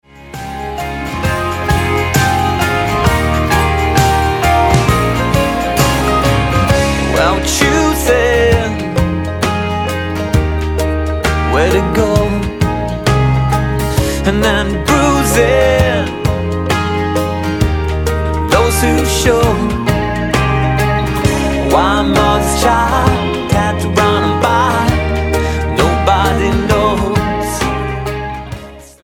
singer songwriter
Style: Roots/Acoustic